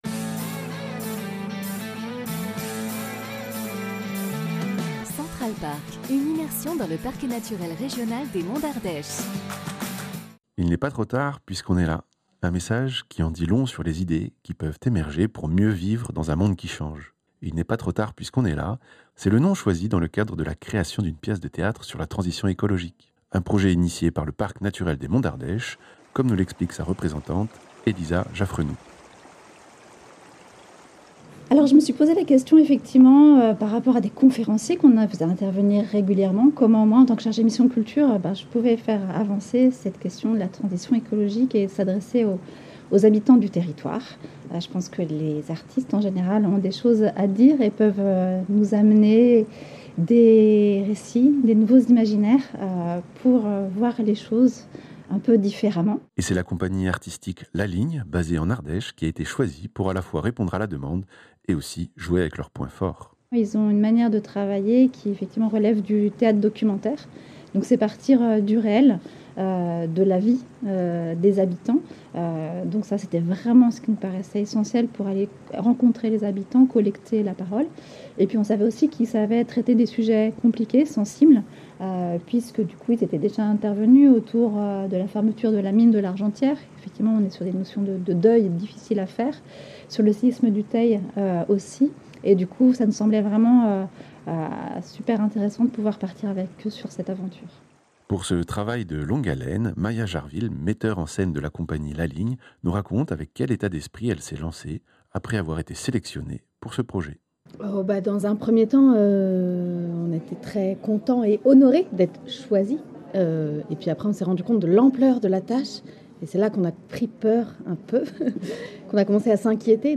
Un reportage sur le projet artistique autour de la transition écologique "Il n'est pas trop tard puisqu'on est là" avec la Compagnie la ligne.